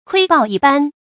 注音：ㄎㄨㄟ ㄅㄠˋ ㄧ ㄅㄢ
窺豹一斑的讀法